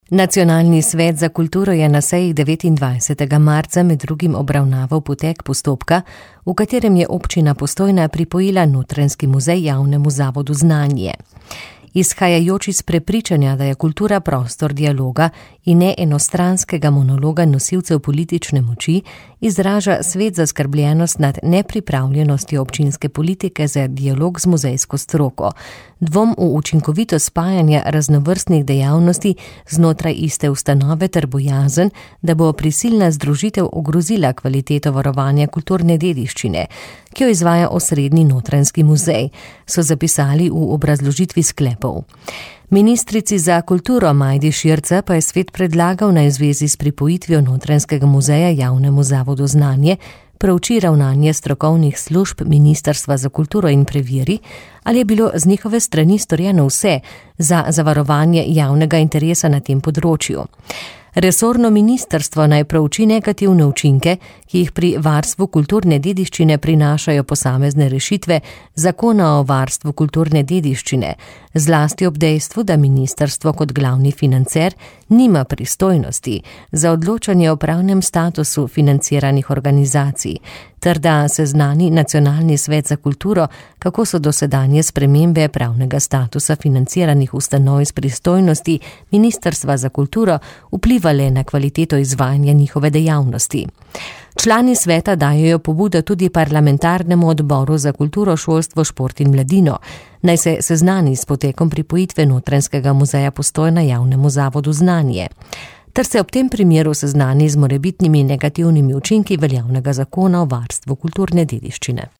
• novice radio94 r94